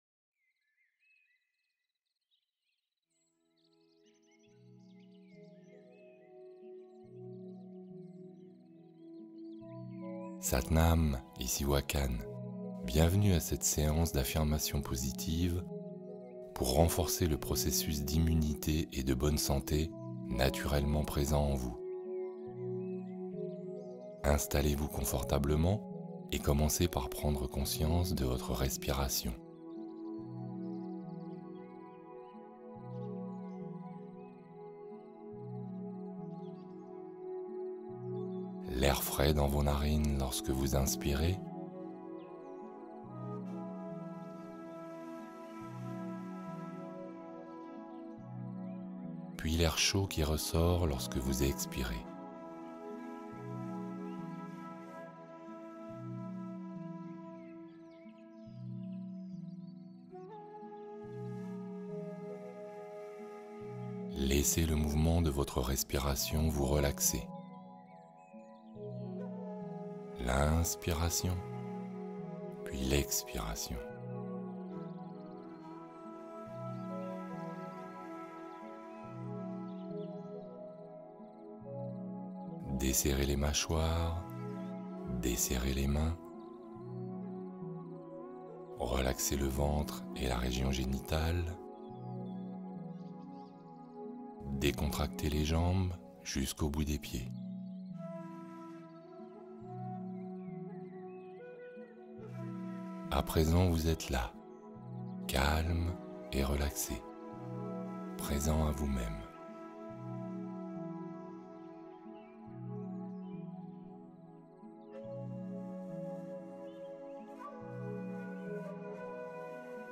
Hypnose du soir : favoriser un repos naturel et fluide